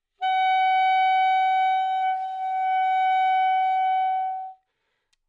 萨克斯中音单音（吹得不好）" 萨克斯中音F5不好的攻击
标签： 好声音 单注 多样本 萨克斯 纽曼-U87 Fsharp5 中音
声道立体声